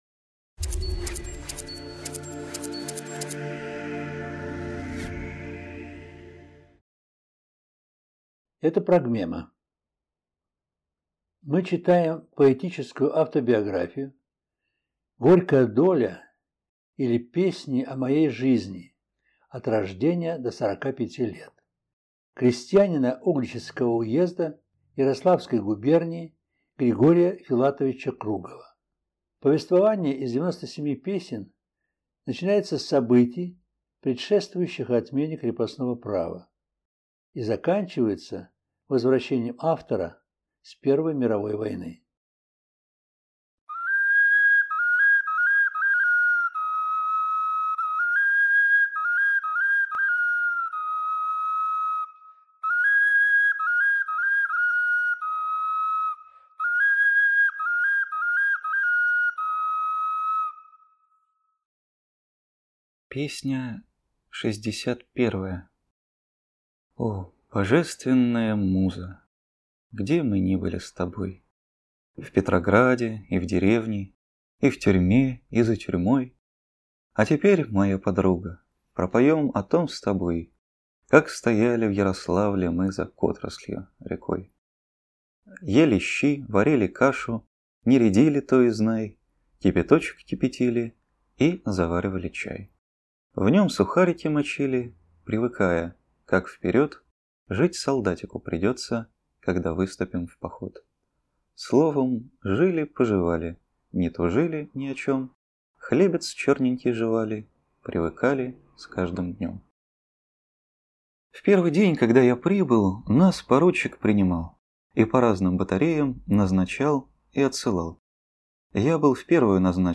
В записи использованы звучание глиняных Скопинских, Суджанских, Чернышенских игрушек-окарин
Песня литературного происхождения на стихи Демьяна Бедного.